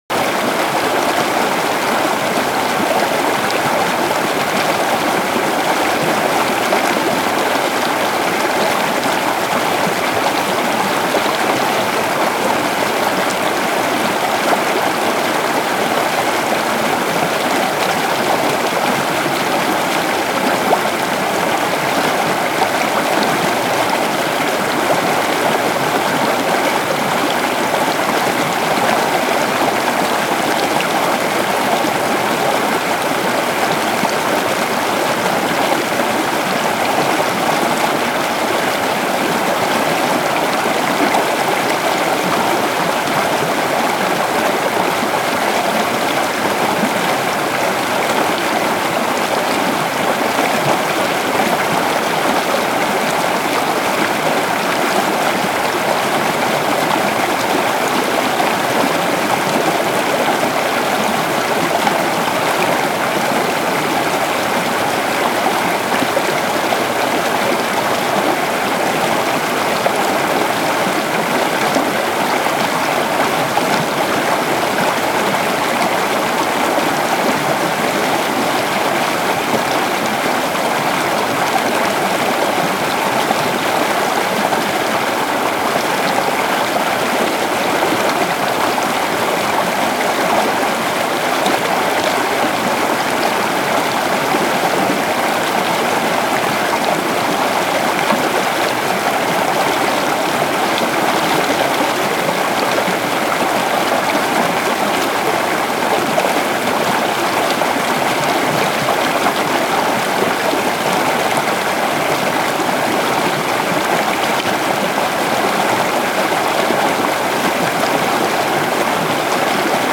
river_strong_loop.ogg